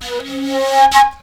FLUT 02.AI.wav